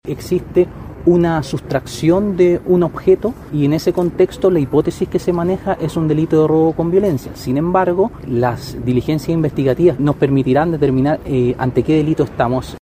El fiscal Denis Pavez, de la Fiscalía Sur, señaló que la investigación determinará si este hecho se dio tras un asalto o presuntamente otra motivación, como una quitada de droga.
fiscal-1.mp3